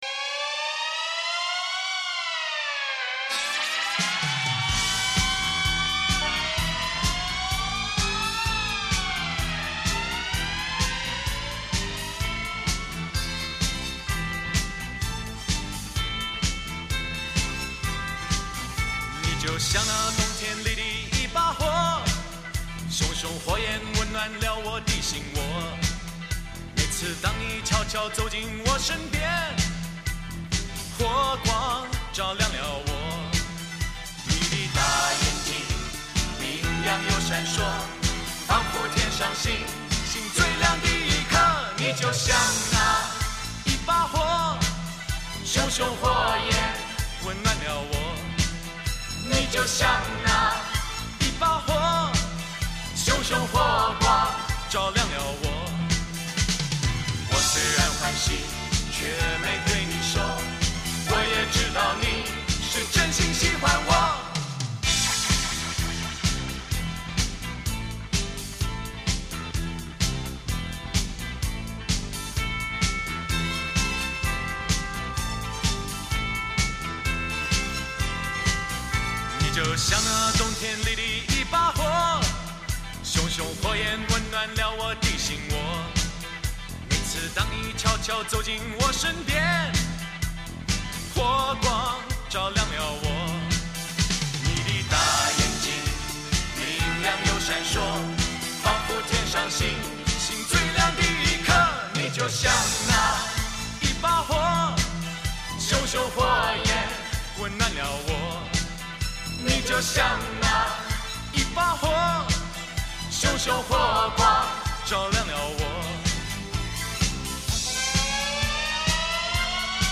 极具魅力的磁性嗓音，再次回荡在你我记忆里。
那淳厚丰满的质感绵密而富有弹性，彷佛触手可及。